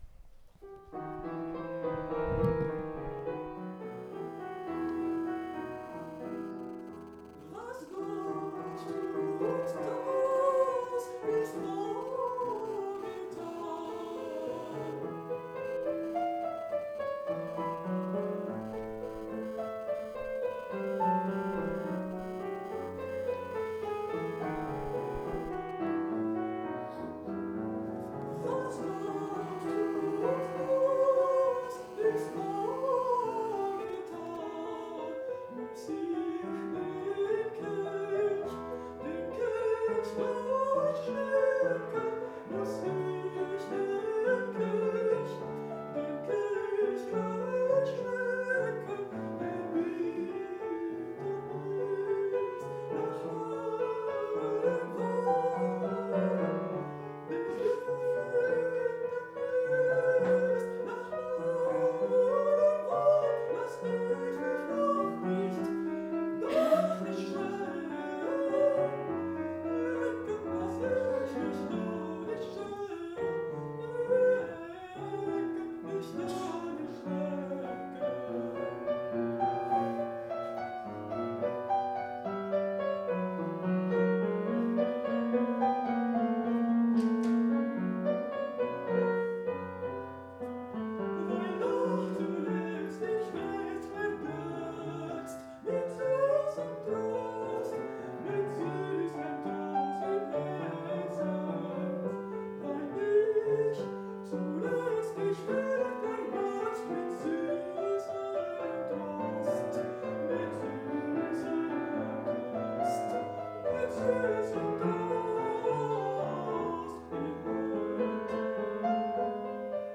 Performance in 2016
April 22, 2016, International Academy of Music, Hourtin, Médoc, France